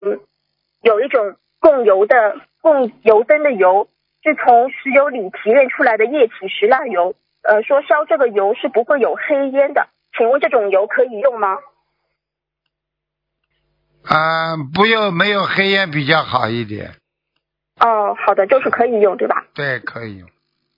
目录：☞ 2019年08月_剪辑电台节目录音_集锦